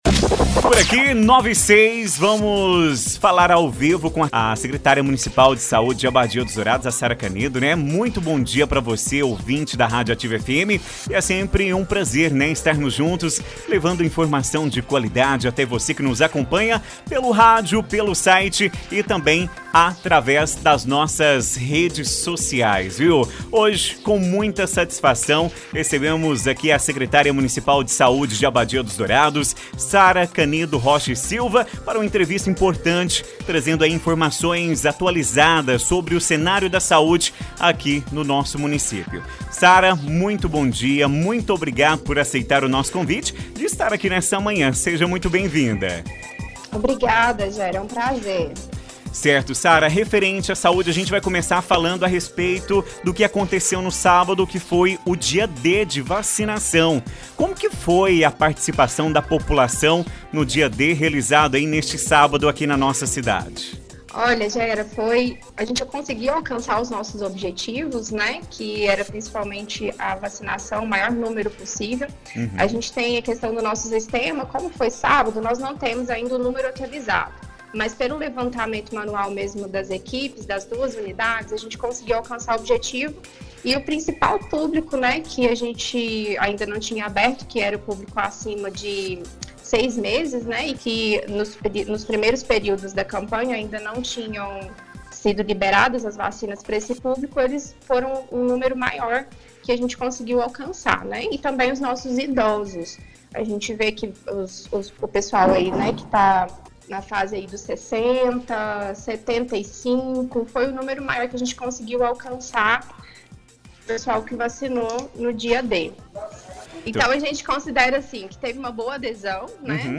Na manhã desta segunda-feira (12), a secretária municipal de Saúde, Sara Canedo Rocha e Silva, participou ao vivo da programação da Rádio Ativa FM 87.9, trazendo informações importantes sobre a saúde pública no município.
INTEGRA-ENTREVISTA-SARA-CANEDO.mp3